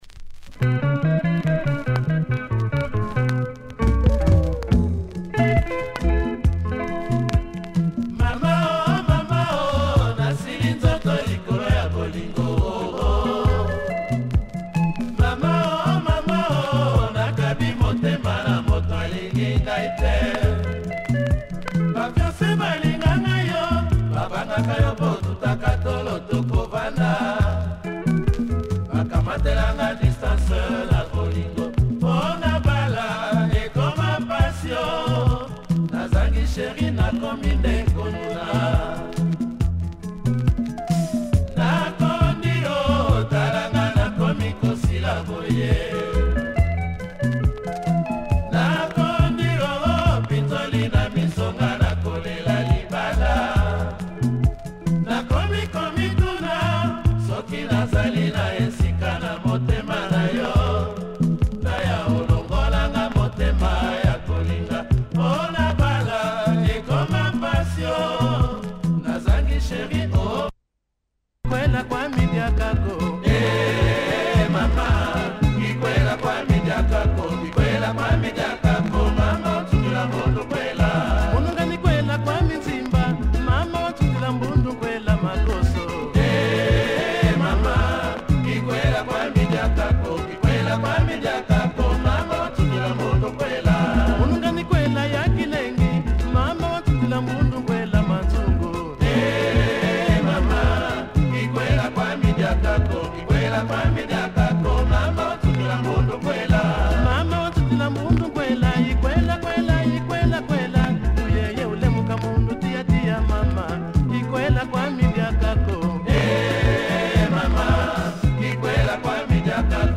looks pretty clean but plays with some noise